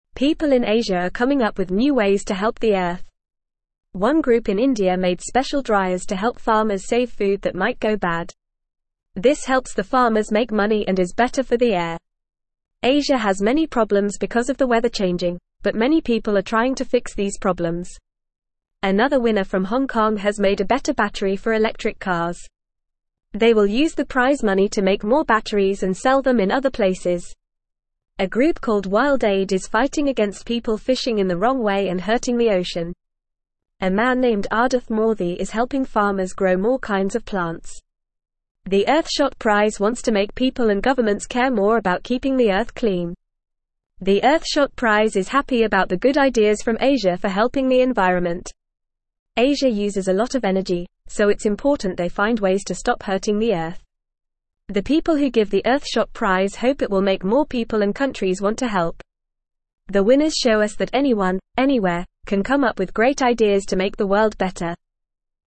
Fast
English-Newsroom-Lower-Intermediate-FAST-Reading-People-in-Asia-Helping-Fix-the-Earth.mp3